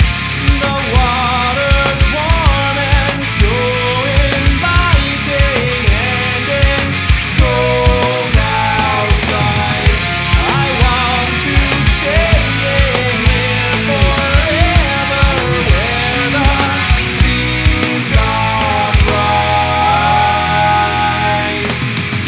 ShowerSong.wav